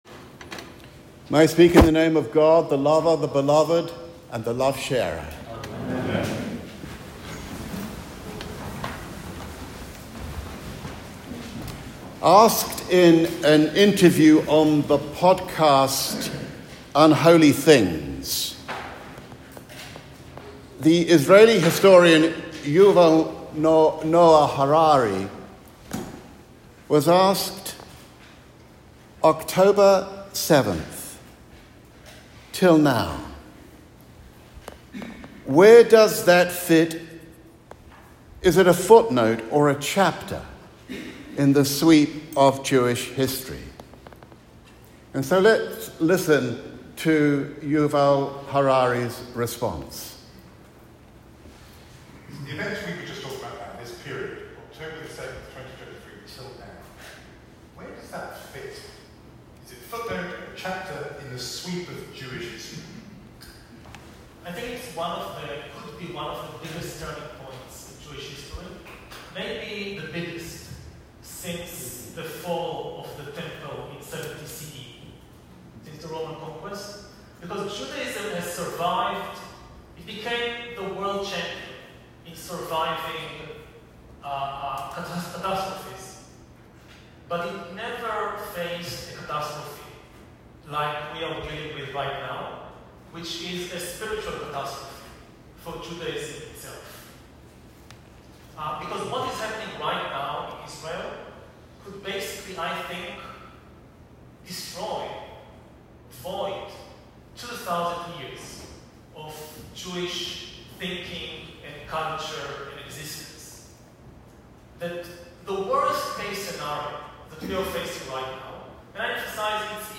Sermon Recording